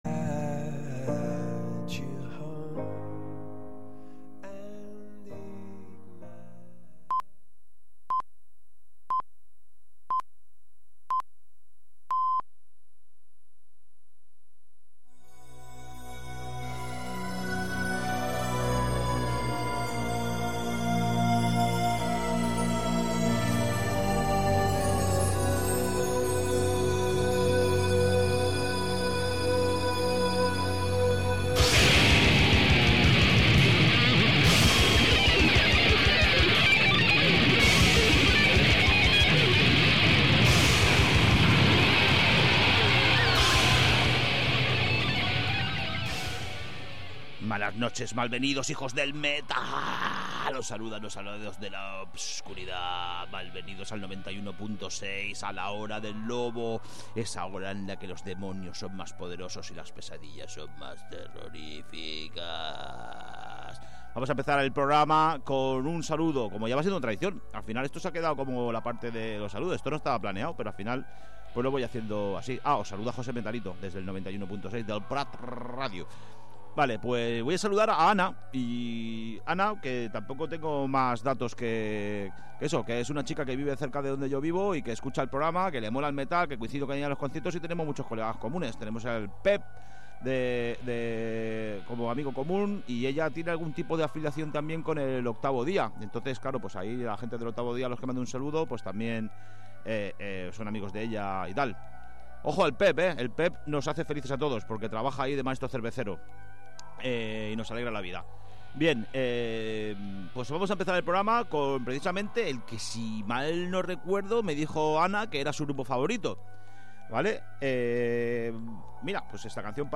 A 'La hora del lobo', ens arrossegarem per tots els subgèneres del metal, submergint-nos especialment en les variants més fosques i extremes.